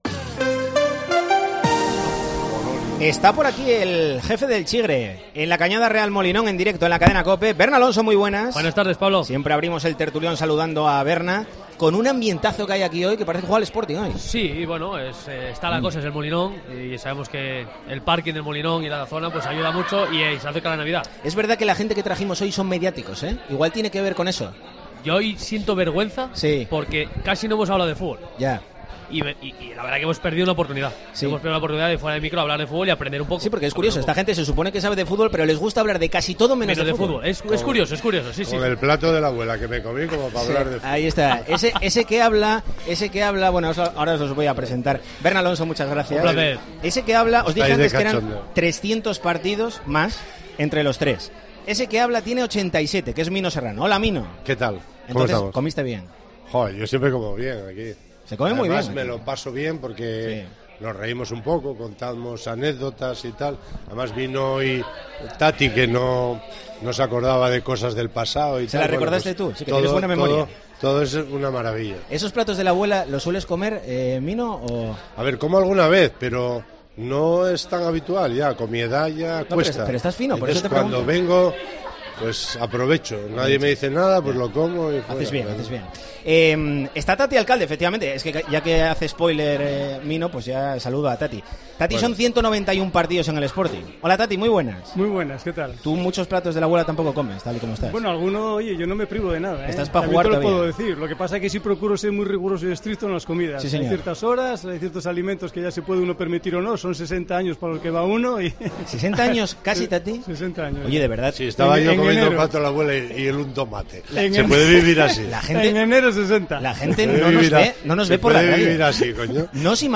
'El Tertulión del Sporting' en Deportes COPE Asturias En un nuevo capítulo de 'El Tertulión del Sporting' desde La Cañada Real Molinón , debatimos acerca de las posibilidades del Sporting de ascender o las claves del gran inicio del equipo.